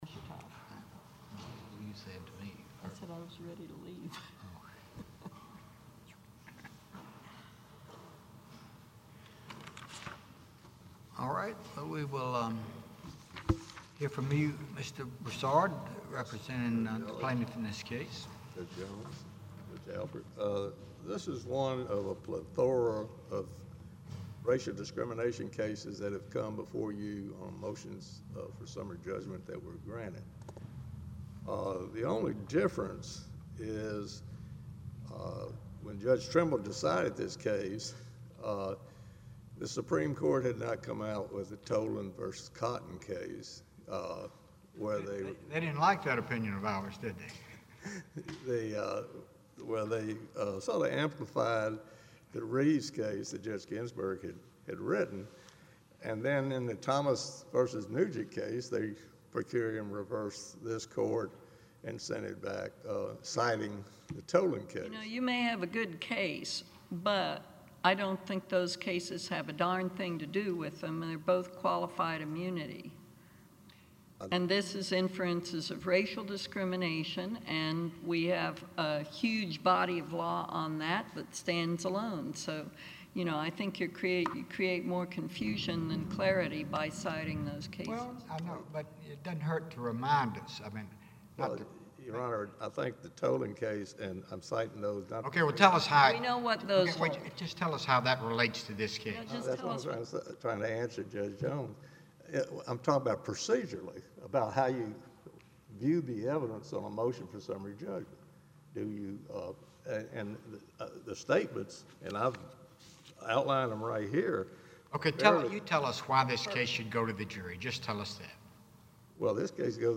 This poor fellow earns the same basic tongue-lashing, just with a different accent…